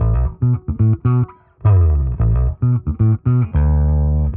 Index of /musicradar/dusty-funk-samples/Bass/110bpm
DF_PegBass_110-B.wav